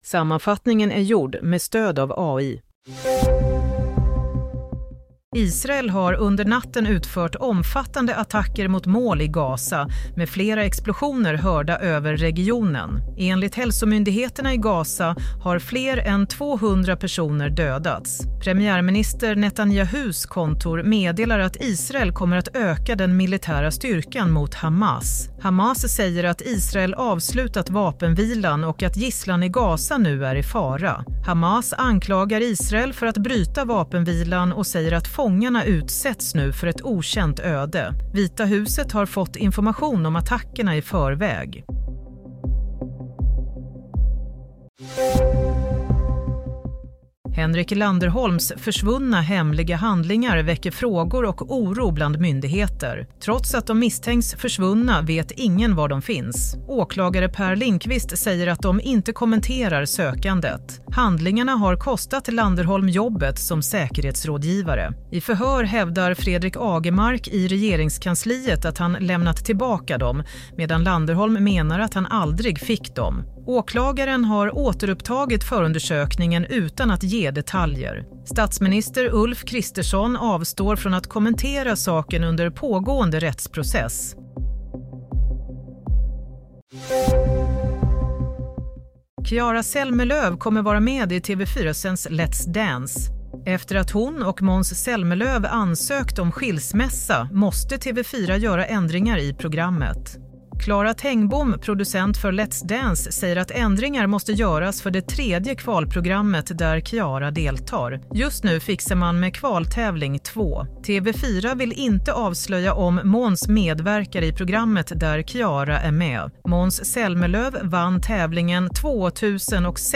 Nyhetssammanfattning - 18 mars 07:00
Sammanfattningen av följande nyheter är gjord med stöd av AI.